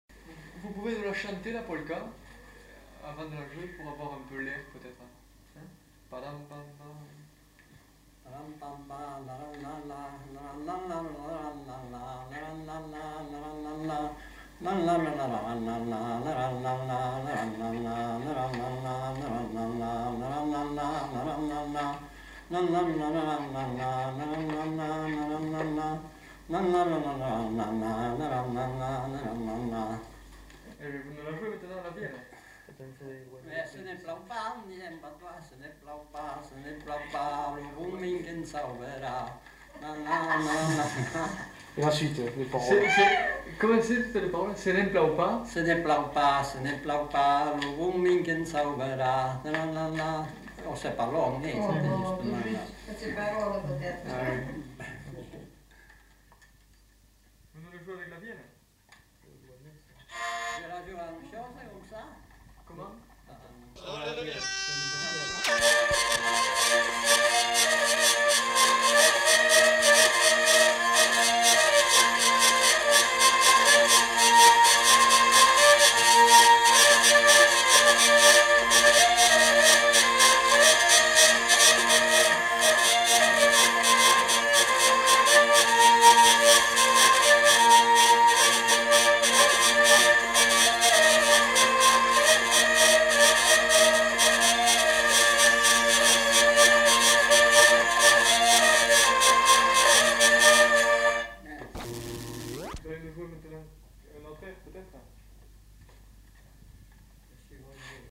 Aire culturelle : Petites-Landes
Genre : morceau instrumental
Instrument de musique : vielle à roue
Danse : polka
Notes consultables : La mélodie est d'abord fredonnée avec quelques paroles données puis elle est jouée à la vielle à roue.